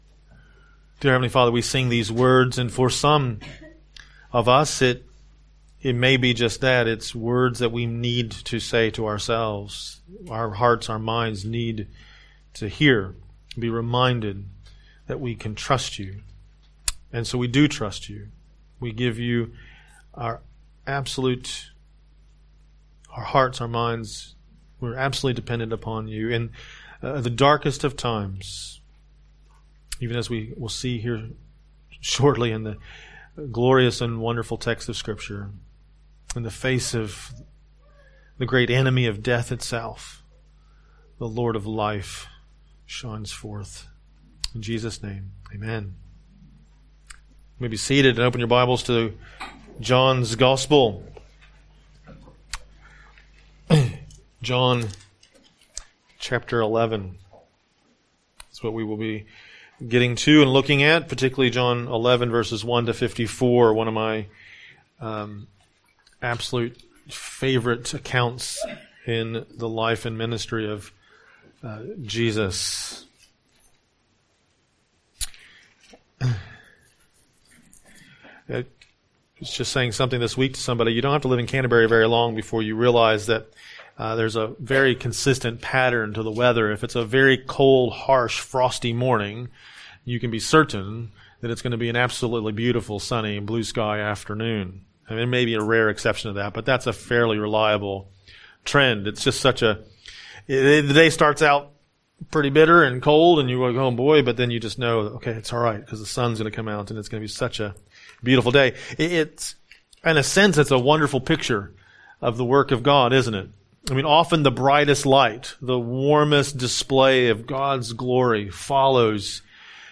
Sermons & Seminars | Rolleston Baptist Church